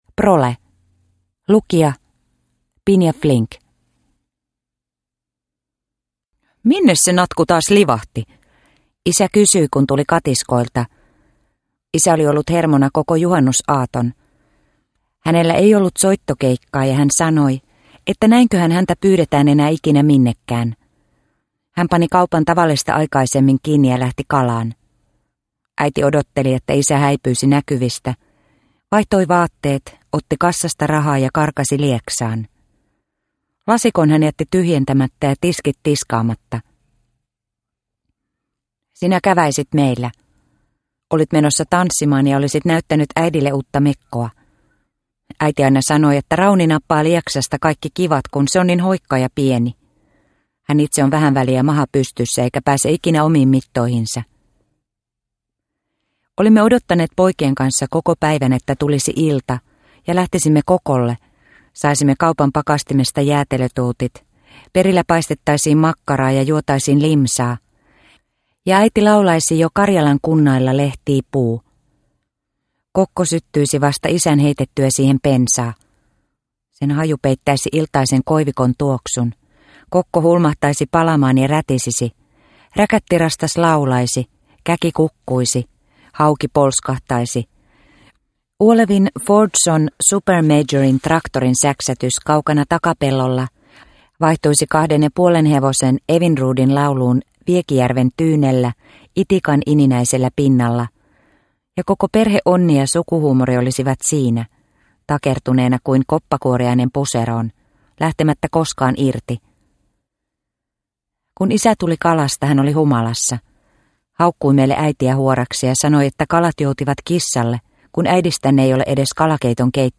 Prole – Ljudbok – Laddas ner